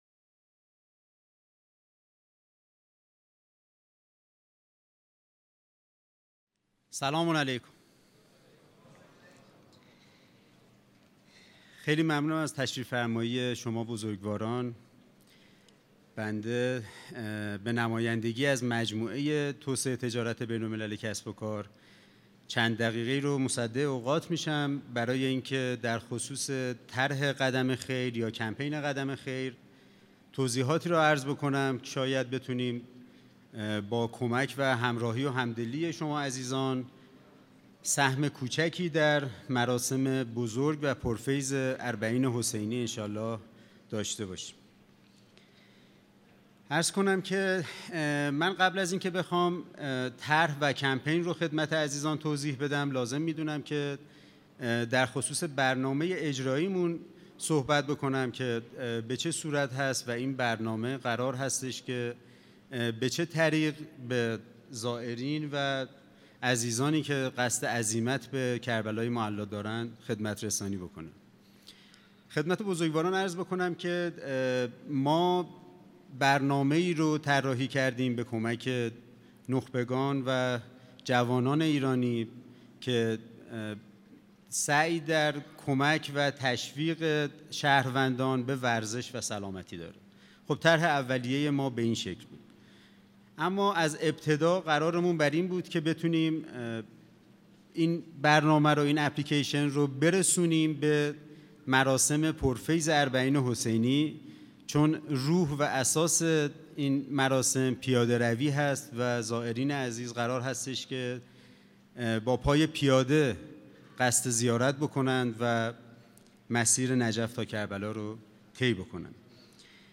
معرفی کمپپین قدم خیر اربعین | هفتمین همایش هیأت‌های فعال در عرصه اربعین | قم - مسجد مقدس جمکران